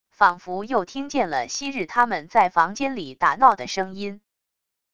仿佛又听见了昔日他们在房间里打闹的声音wav音频生成系统WAV Audio Player